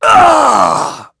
Clause_ice-Vox_Damage_05.wav